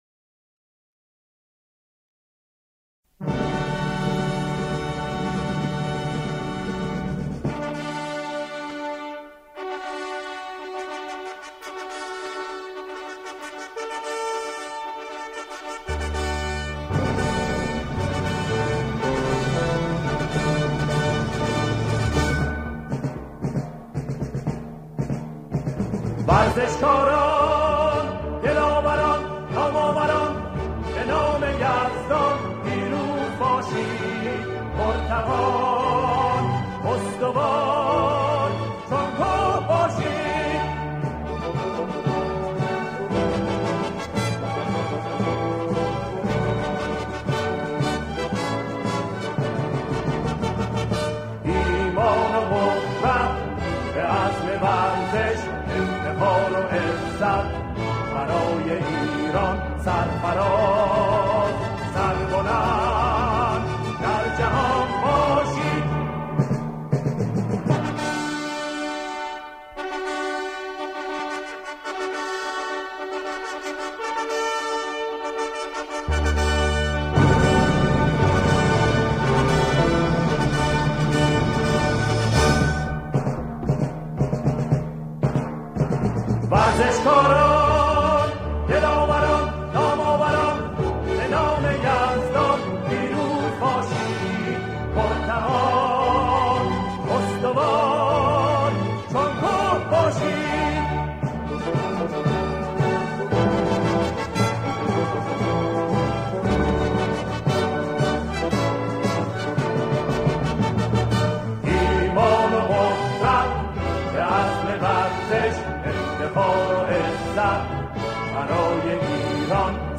سرودهای ورزشی